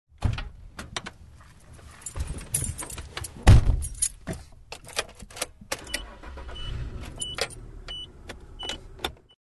Звуки легкового автомобиля
Звук: человек открывает дверь машины, садится внутрь и заводит двигатель (атмосферный)